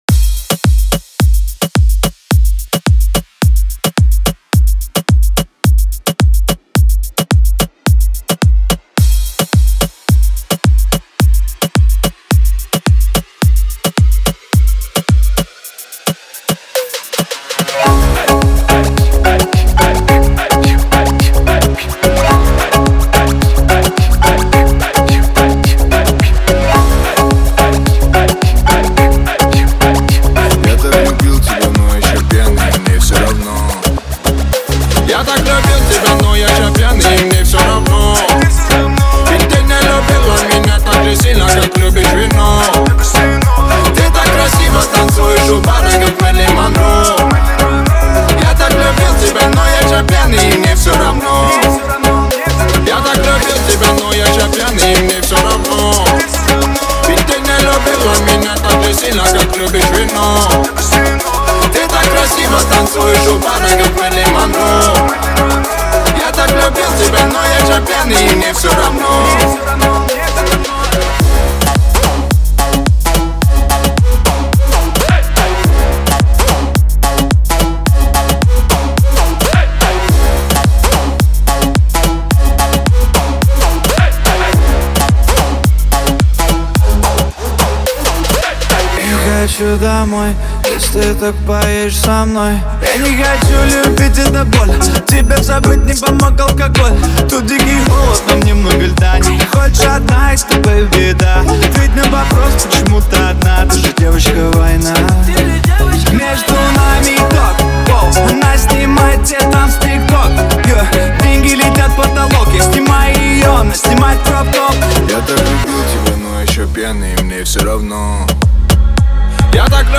это зажигательный трек в жанре поп и хип-хоп